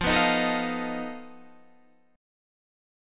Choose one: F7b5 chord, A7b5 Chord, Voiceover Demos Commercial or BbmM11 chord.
F7b5 chord